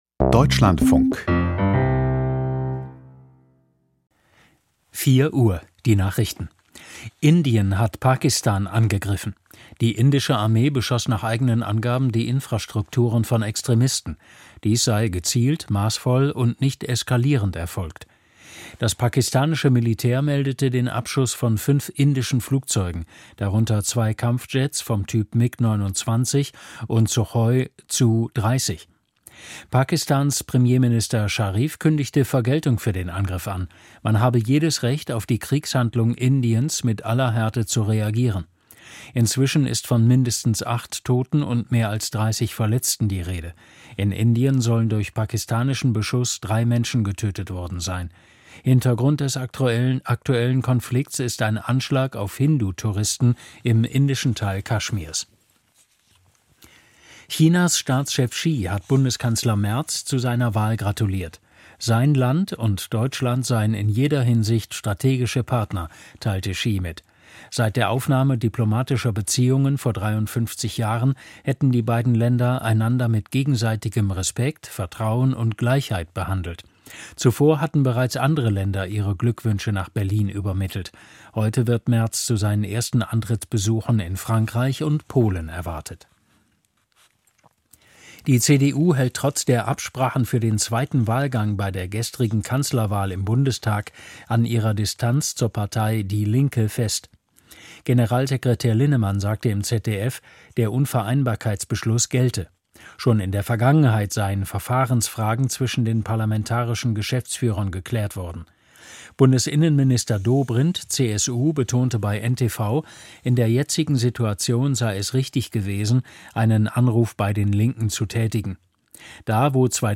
Die Deutschlandfunk-Nachrichten vom 07.05.2025, 04:00 Uhr